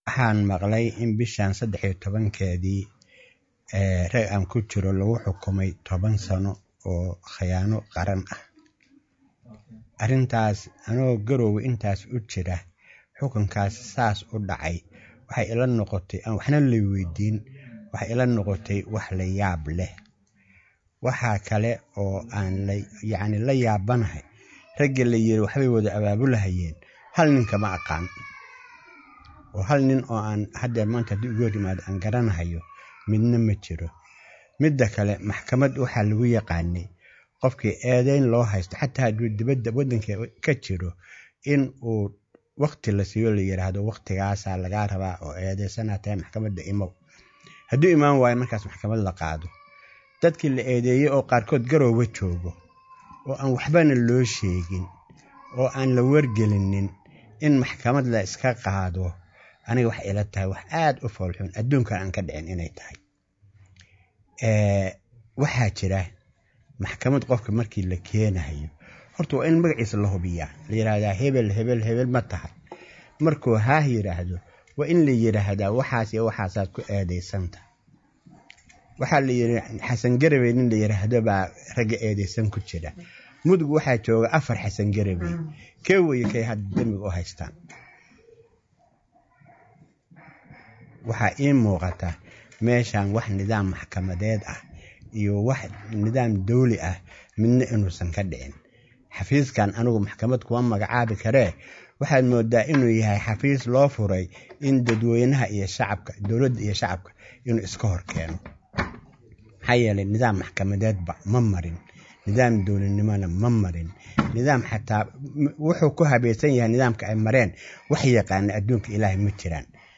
DHEGAYSO SHIR JARAA’ID.